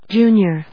音節ju・nior 発音記号・読み方
/dʒúːnjɚ(米国英語), dʒúːnjə(英国英語)/